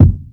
Perfect Kick Drum Single Hit F Key 161.wav
Royality free kick drum one shot tuned to the F note. Loudest frequency: 134Hz
perfect-kick-drum-single-hit-f-key-161-mQX.mp3